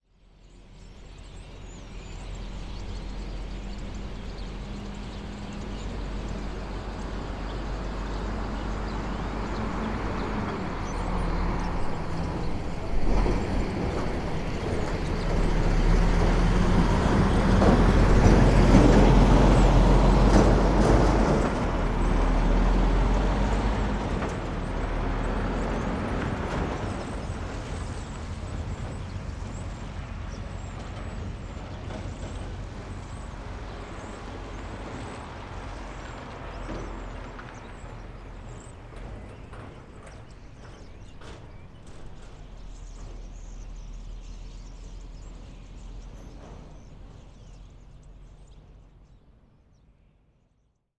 ambience
Dock Ambience - Trucks, Birds